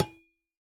Minecraft Version Minecraft Version latest Latest Release | Latest Snapshot latest / assets / minecraft / sounds / block / copper / break3.ogg Compare With Compare With Latest Release | Latest Snapshot